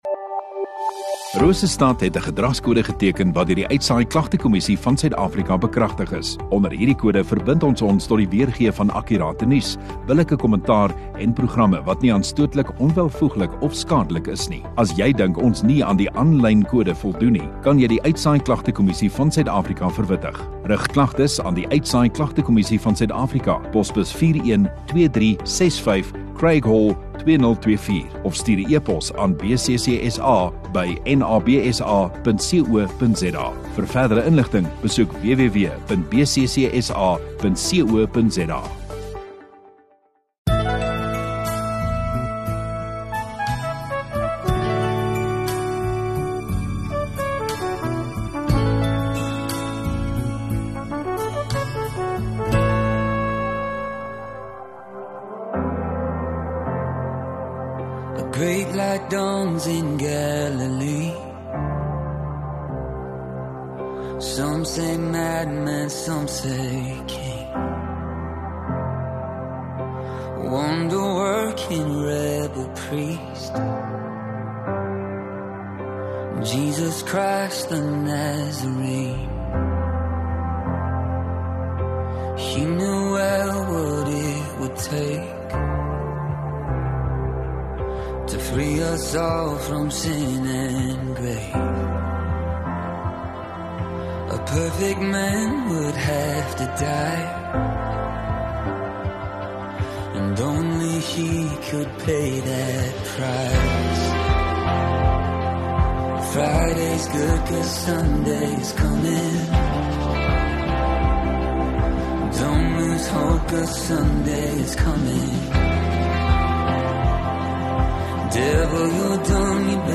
21 Dec Saterdag Oggenddiens